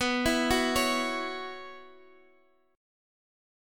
Listen to Bsus2sus4 strummed